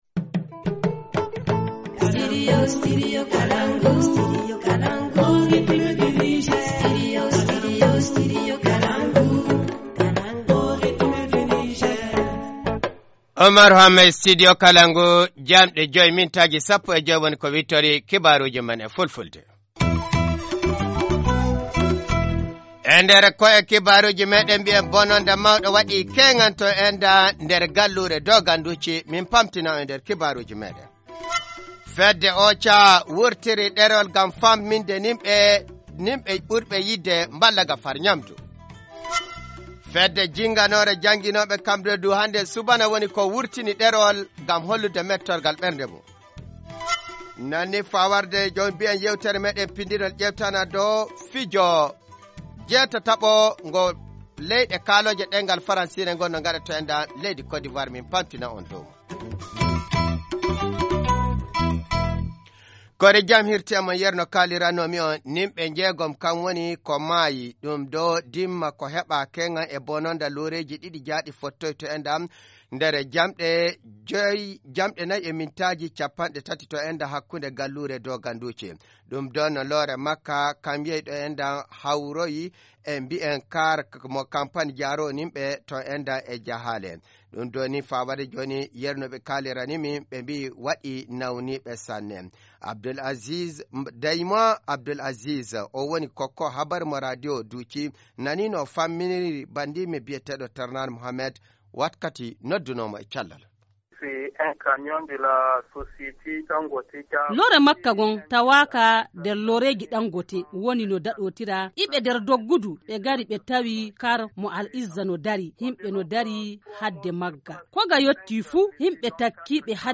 Journal du 28 juillet 2017 - Studio Kalangou - Au rythme du Niger